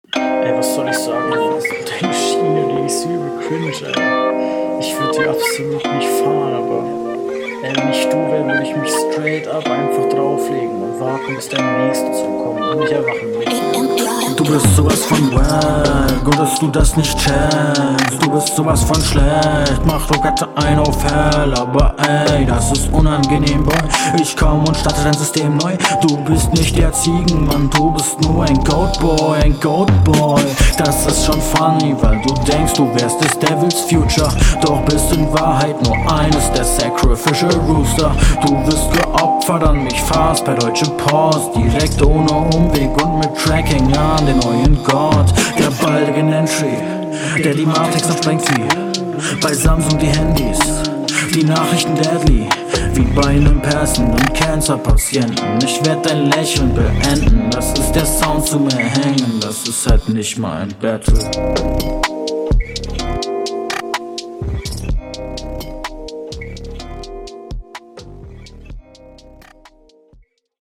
Flow:ka anfang holt mich nicht wirklich ab, bisschen zu slow alles nach meinem geschmack, passt …
Flow: sehr sehr stabil, variierst immer mal und wie du langgezogenen silben platzierst, klingt sehr …
Flow: Halftime und sehr schleppend was für mich anstrengend ist ein wenig aber wenn gute …